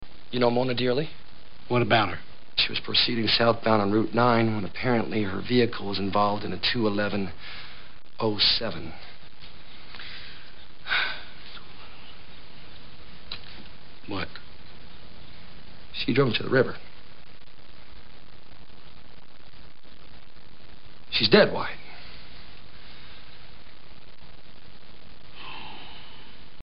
Category: Movies   Right: Personal